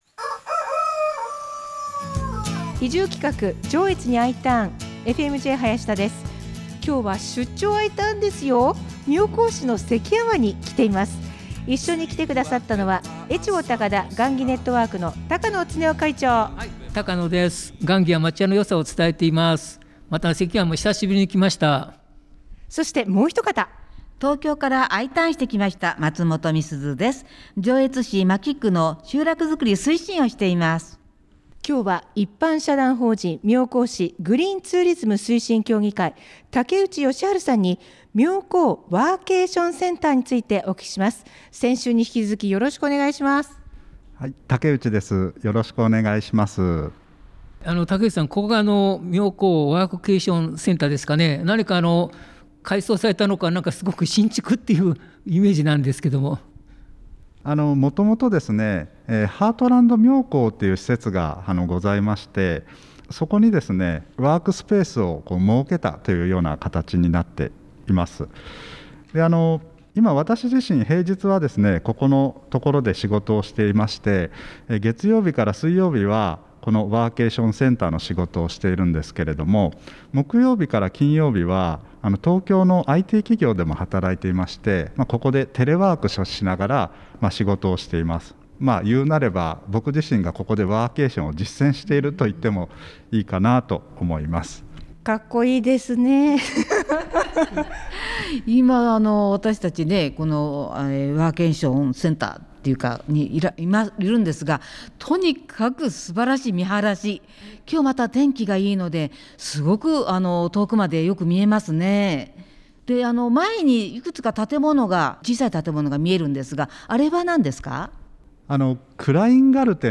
妙高ワーケーションセンターに伺いました。